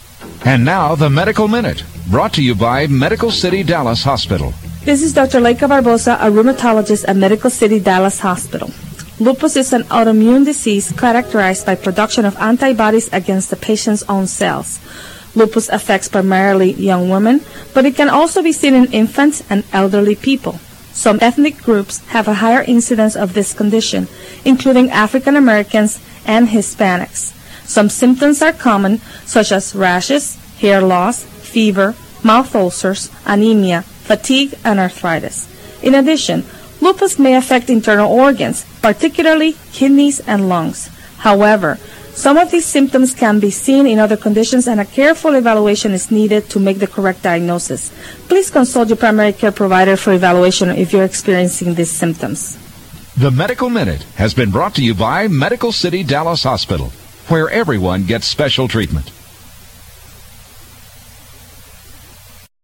Radio Spot AM 1080 KRLD
radiospot.mp3